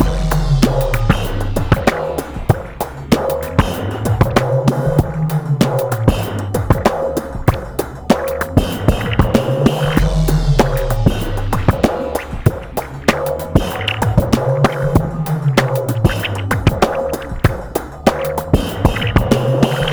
42 FUNK INC.-96.3 bpm c.wav